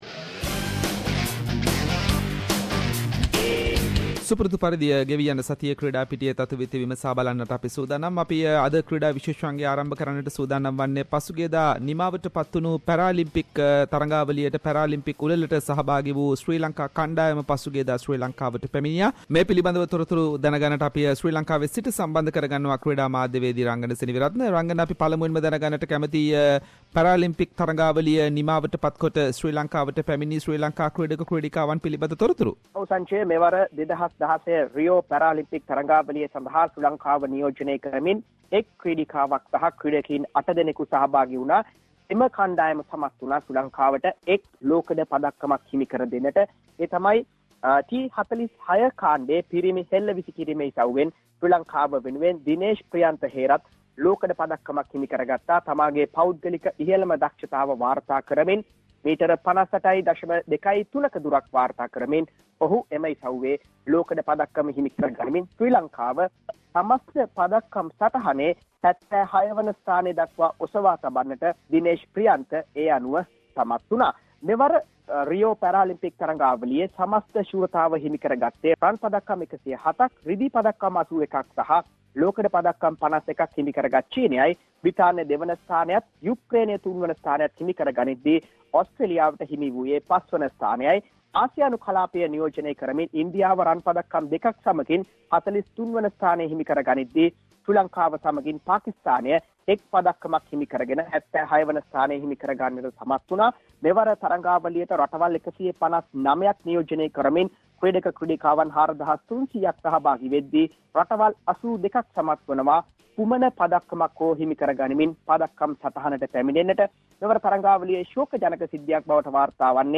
Sports journalist